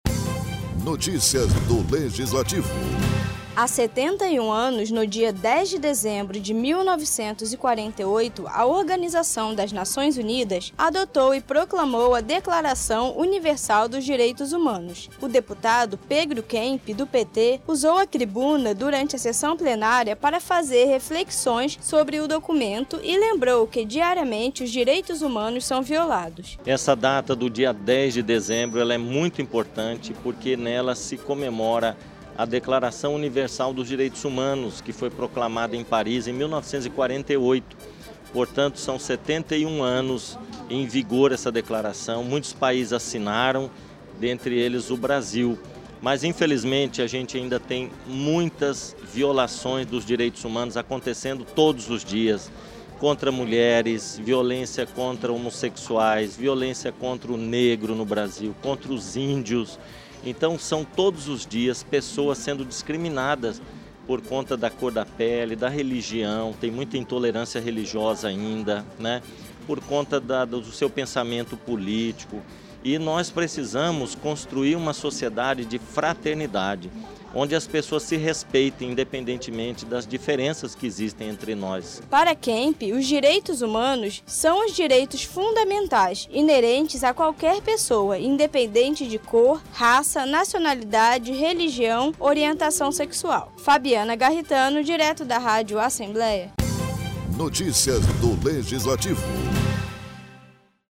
Na sessão desta terça-feira, o deputado estadual Pedro Kemp fez uma reflexão dos 71 anos do documento e lembrou que os direitos humanos ainda são violados diariamente.